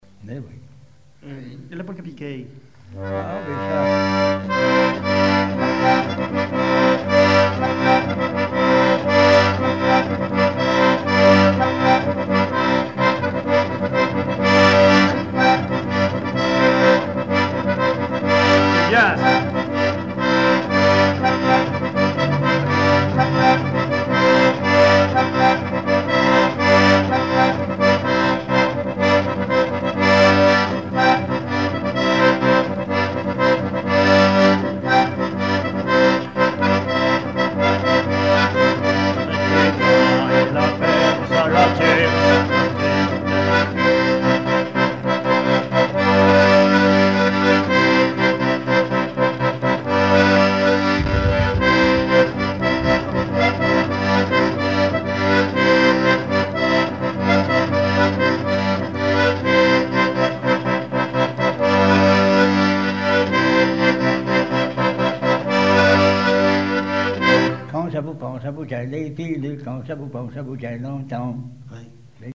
Chants brefs - A danser
danse : polka piquée
chansons populaires et instrumentaux
Pièce musicale inédite